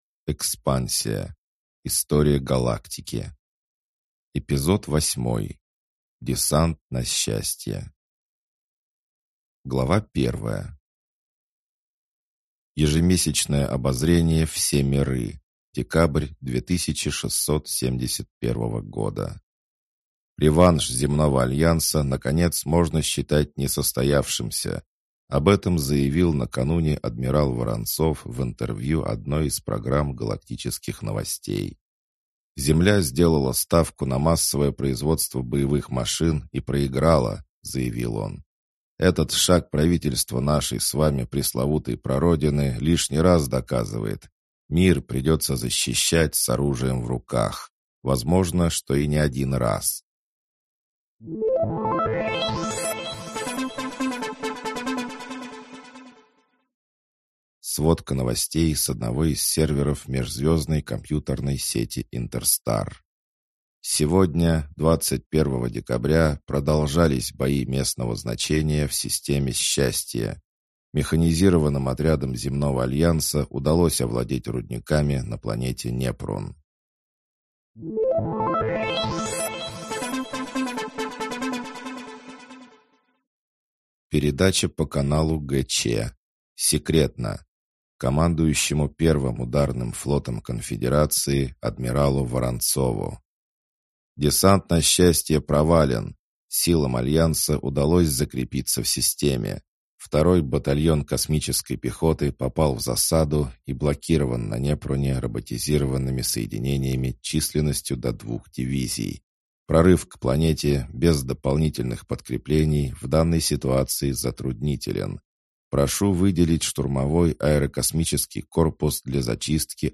Аудиокнига Десант на Счастье | Библиотека аудиокниг